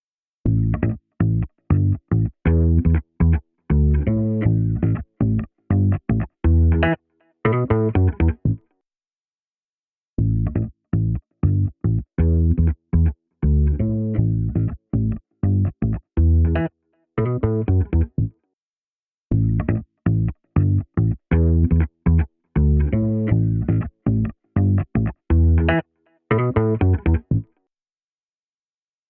EQ45 | Bass | Preset: Brighter Bass
EQ45-Brighter-Bass.mp3